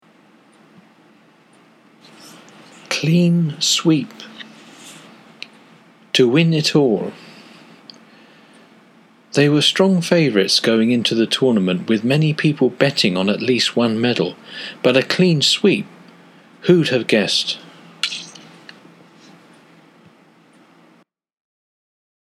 とくに選挙やスポーツの試合における圧勝を指します。 英語ネイティブによる発音は下記のリンクをクリックしてください。